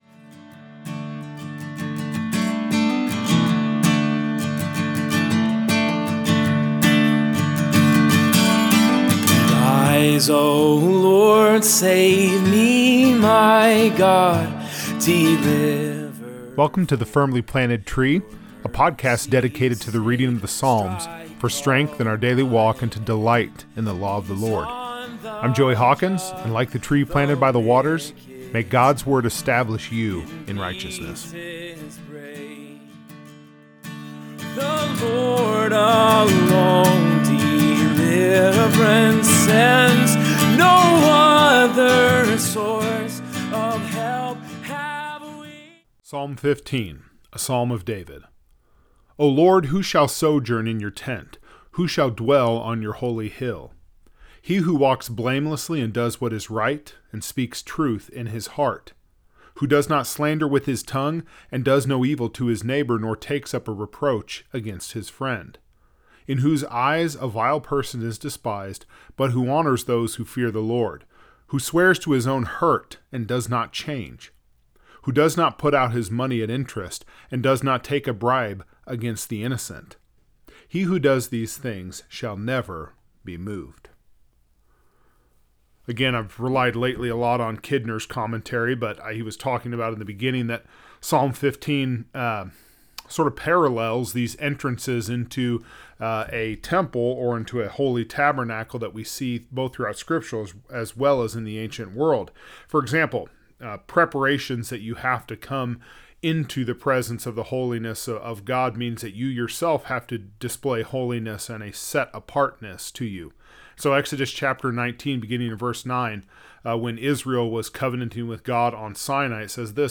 In this episode, we read and reflect on Psalm 15, a psalm that resembles the holiness requirements to enter the tabernacle yet drives us straight to the character and integrity of the upright in heart.